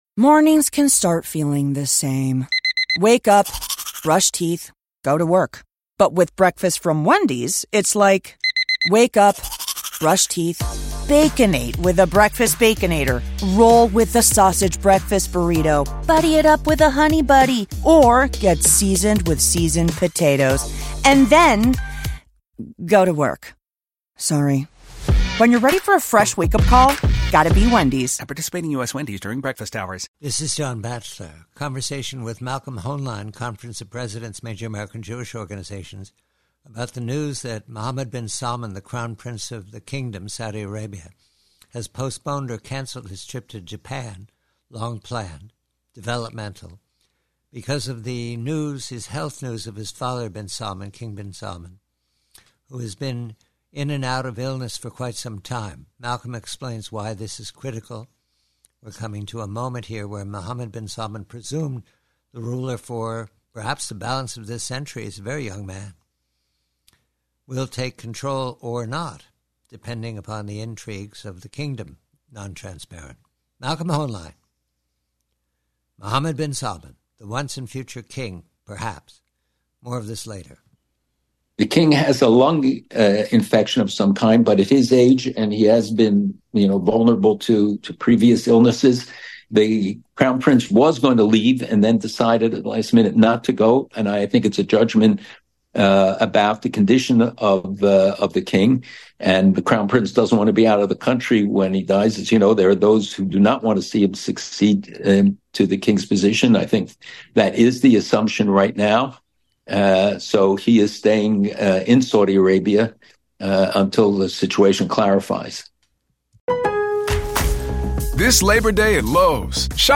PREVIEW: SAUDI ARABIA: Conversation with colleague Malcolm Hoenlein re the decision by the Crown Prince MBS of Saudi Arabia to postpone his embassy to Japan because of the King's health crisis.